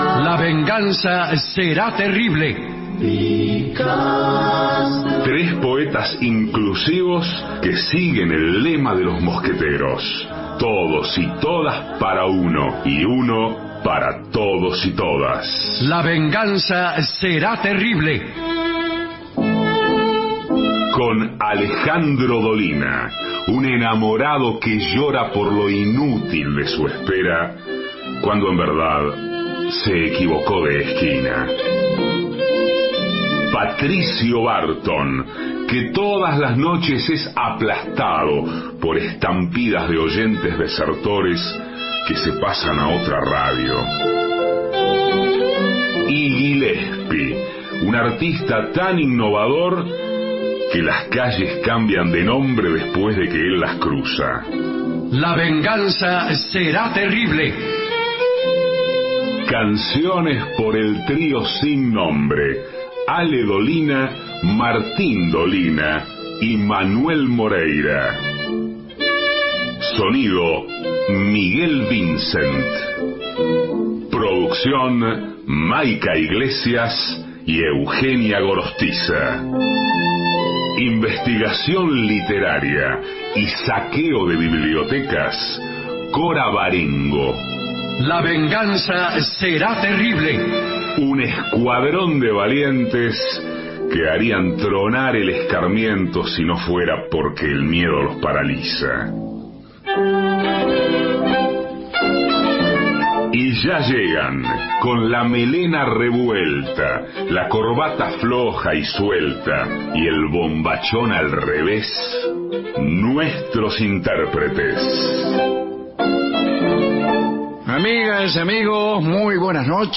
Estudios Radio AM 750 Alejandro Dolina